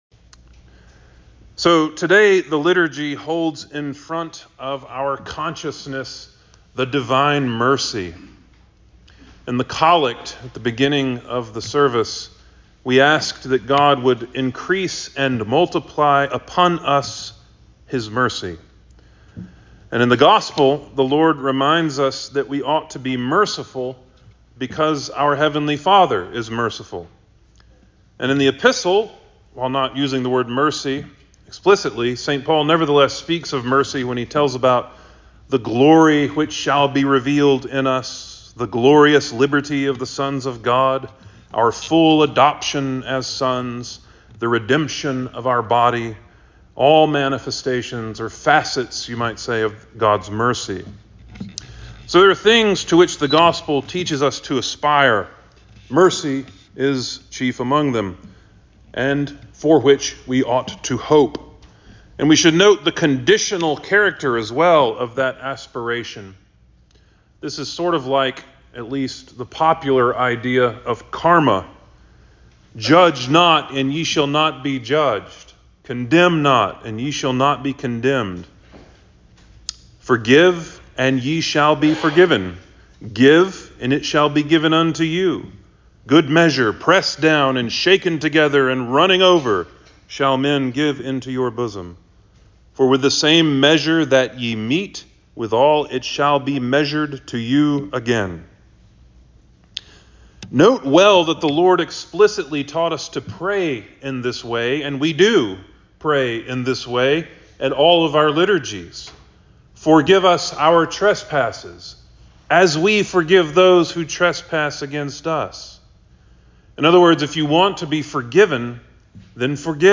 All Saints Sermons